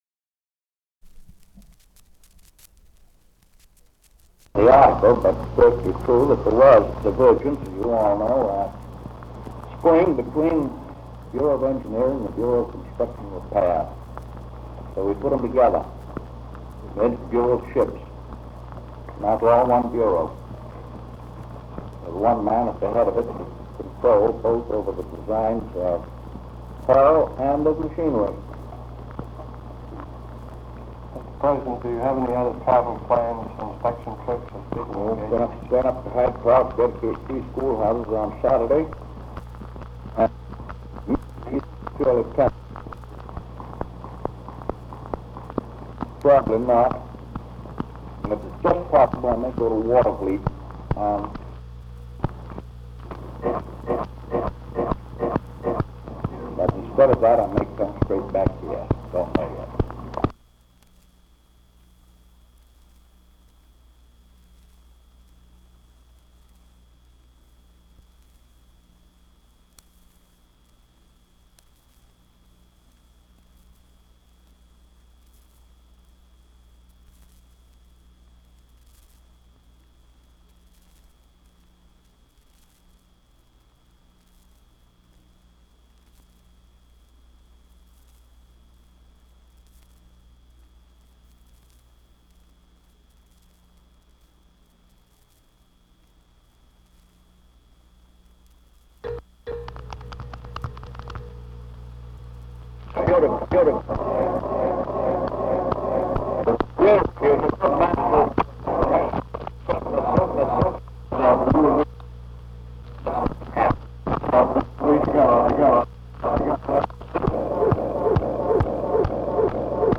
Press Conference 686
Secret White House Tapes | Franklin D. Roosevelt Presidency